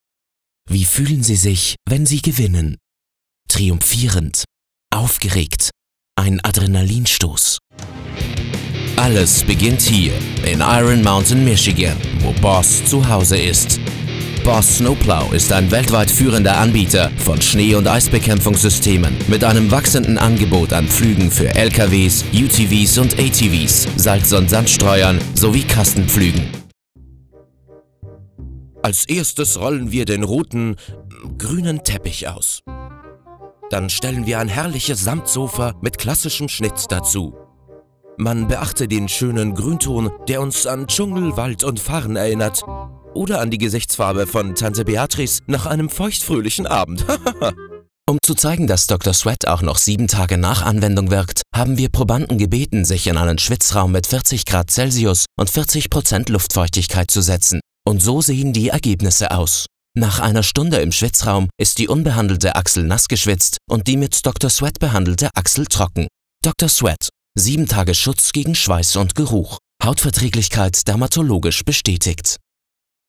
Klangfarben: ruhig, smart, kräftig, dynamisch aber auch gerne schrill, kratzig und frech.
Sprechprobe: Werbung (Muttersprache):
WERBE MIX Herbst 2019.mp3